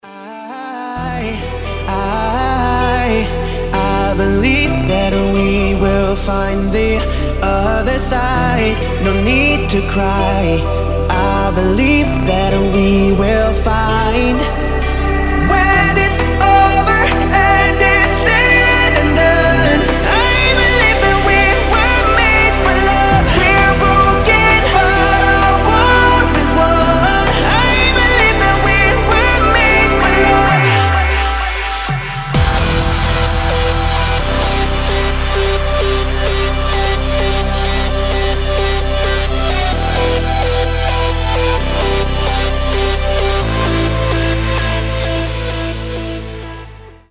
• Качество: 320, Stereo
мужской вокал
мелодичные
EDM
нарастающие
Trance
vocal trance
Транс-музыка с мужским вокалом